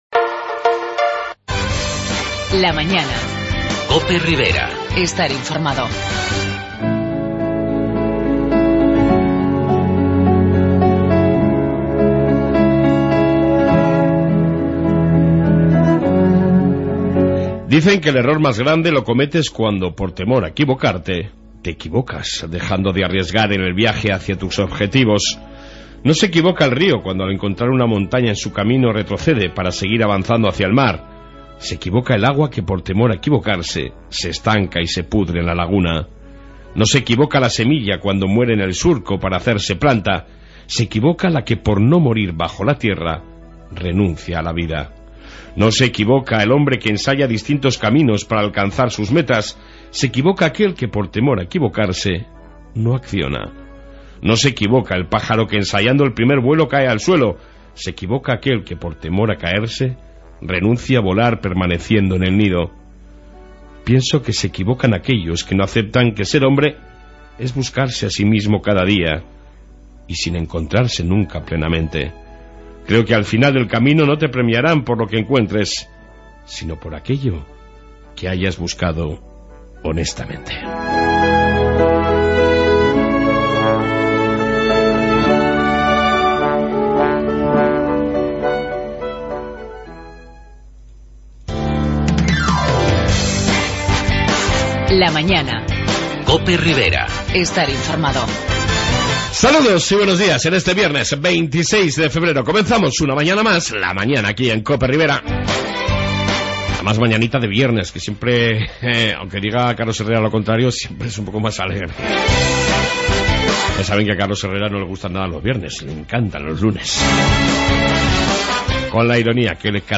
AUDIO: En esta 1ª parte reflexión diaria, Informe Pol municipal, Entrevista sobre la IV edición del festival de Chirijotas de Murchante e...